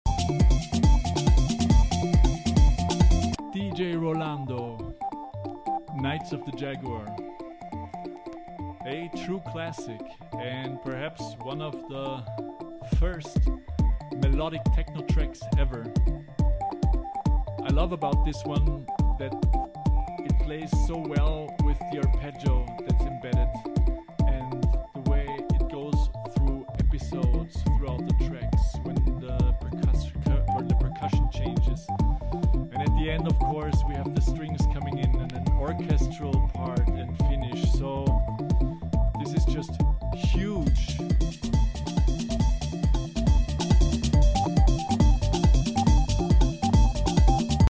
early classic in melodic techno